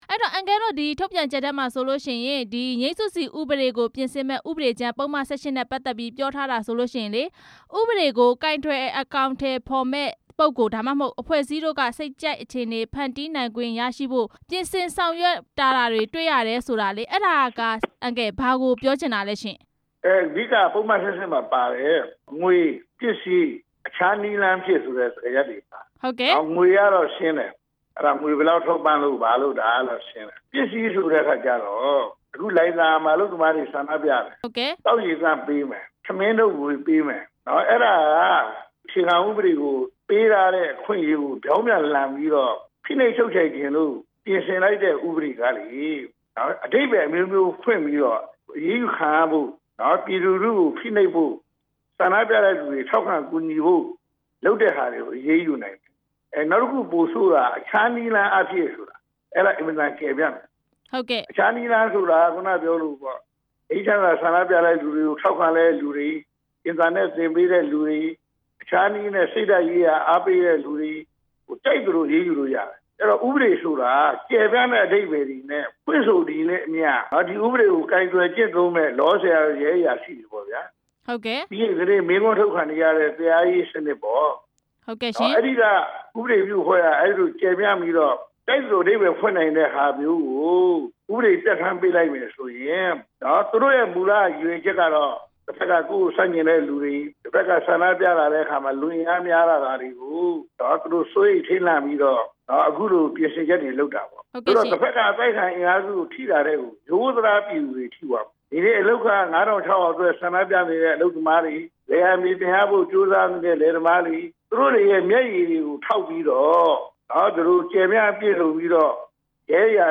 ငြိမ်းစုစီဥပဒေပြင်ဆင်မှု ပါတီ ၂၁ ခု ကန့်ကွက်တဲ့အကြောင်း မေးမြန်းချက်